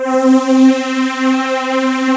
MURKY MOOG 1.wav